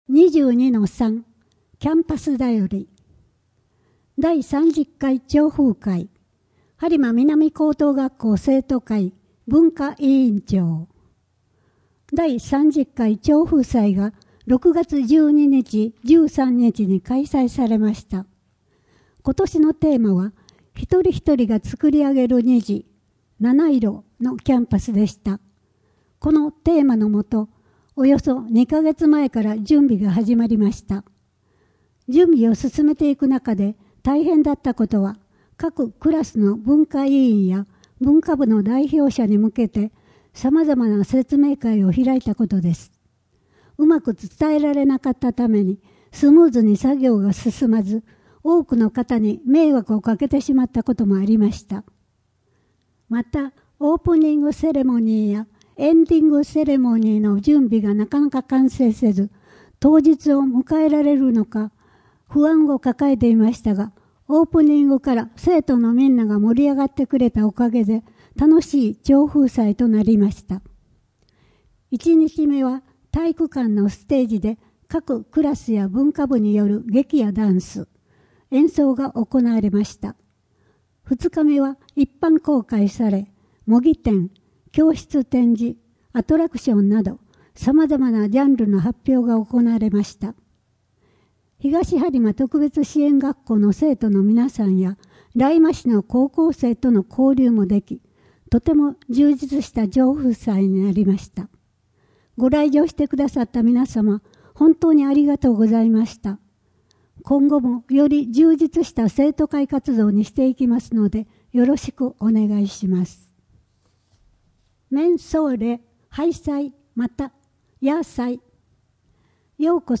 声の「広報はりま」9月号
声の「広報はりま」はボランティアグループ「のぎく」のご協力により作成されています。